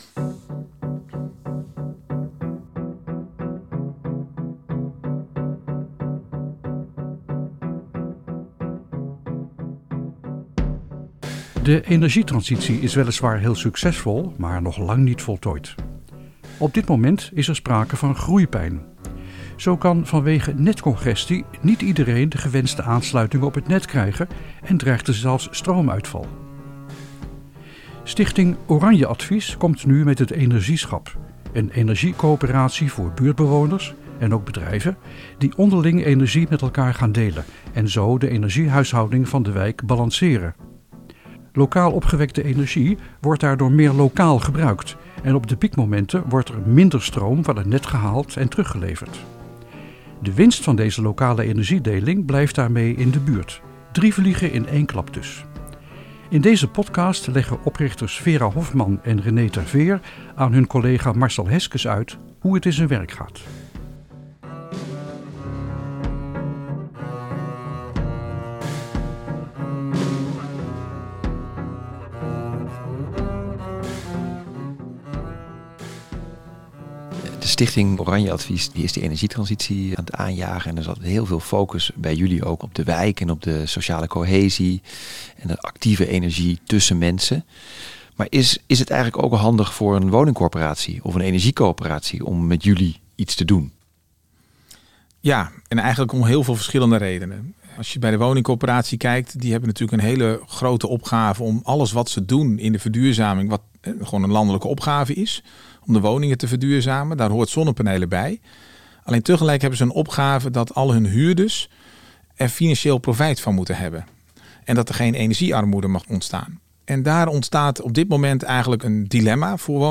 Onze presentatie start op 38:52 Presentatie Groene Huisvesters